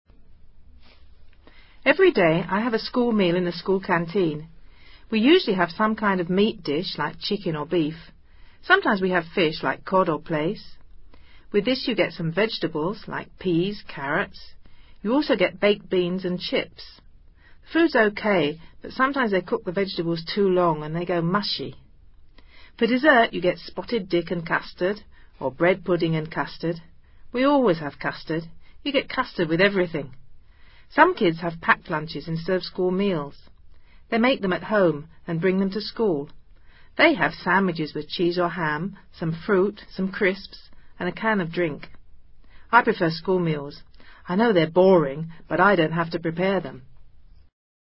Listening: food & drink 4
Una joven describe el menú de su escuela.